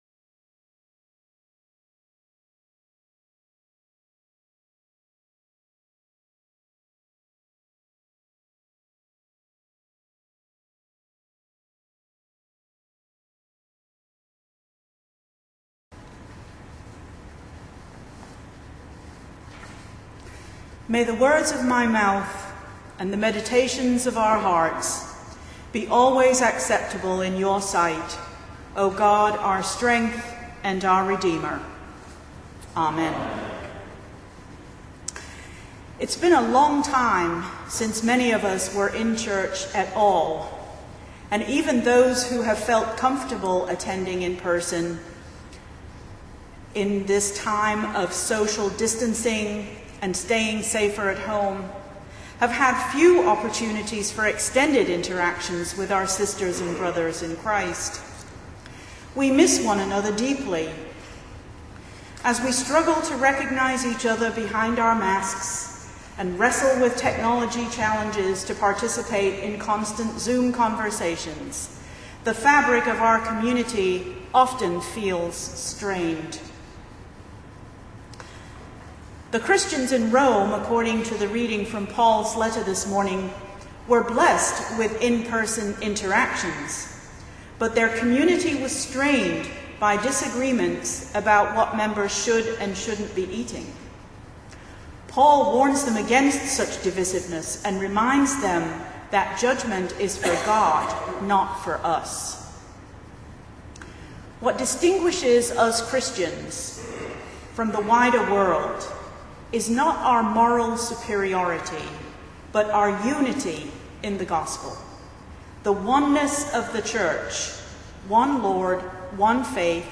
In today’s sermon